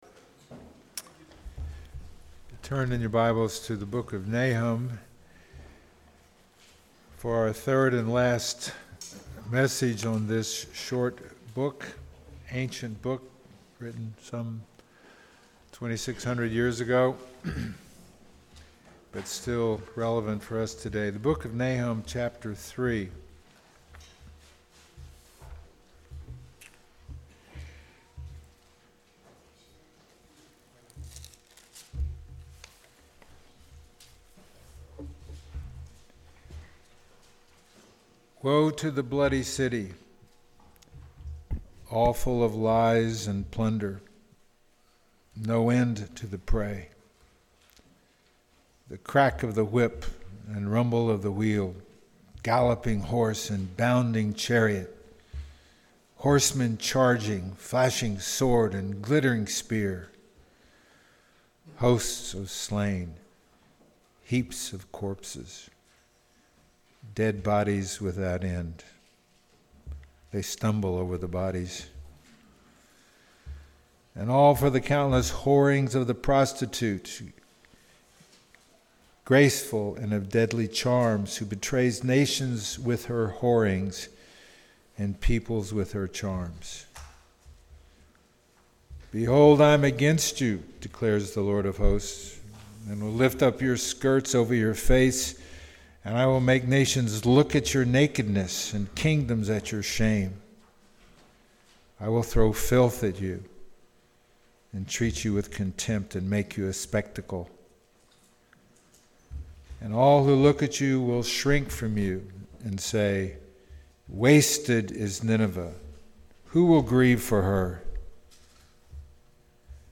Passage: Nahum 3 Service Type: Sunday Morning